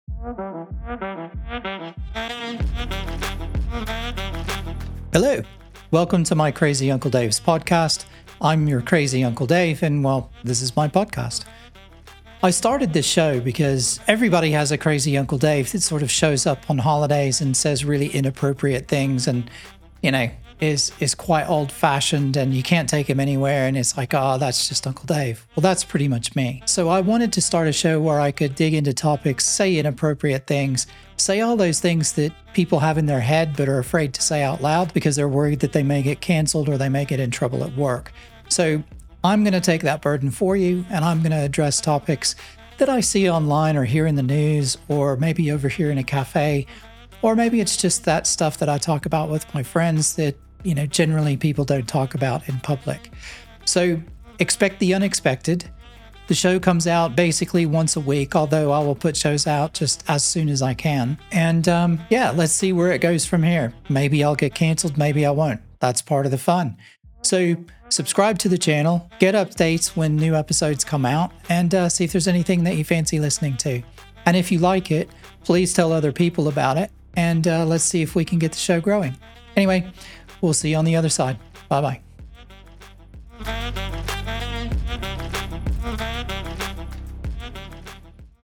trailer-v2.mp3